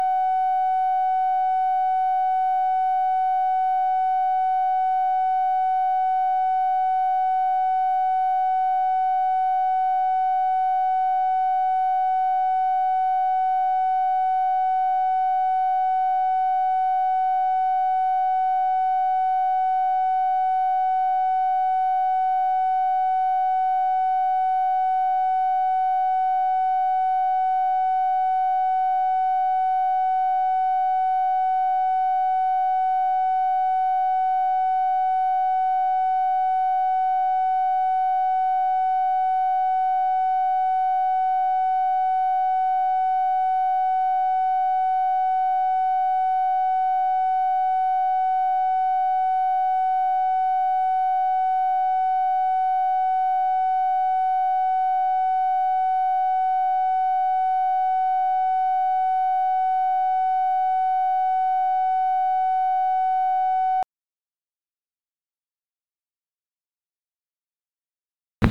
Conversation with UNIDENTIFIED MALE
Secret White House Tapes | Lyndon B. Johnson Presidency